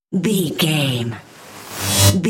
Riser sci fi electronic flashback
Sound Effects
Atonal
futuristic
intense
tension